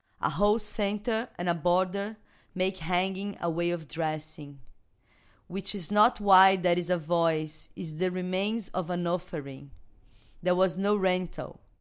声道立体声